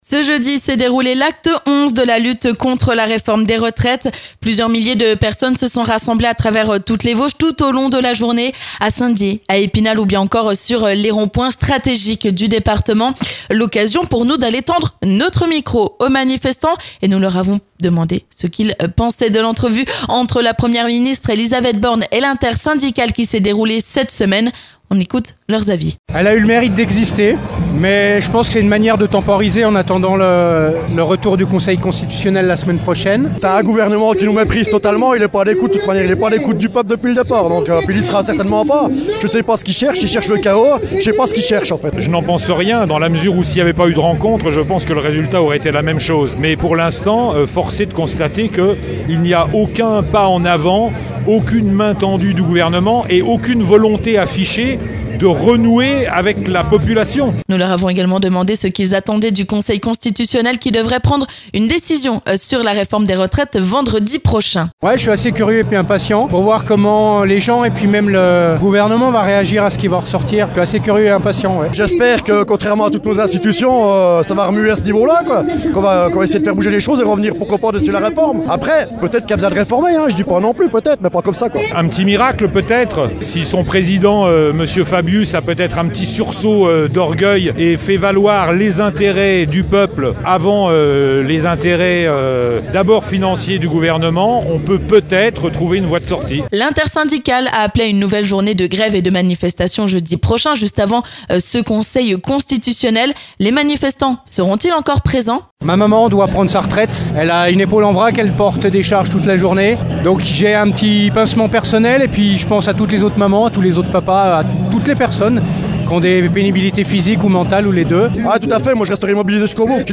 Nous avons tendu notre micro au coeur de la manifestation.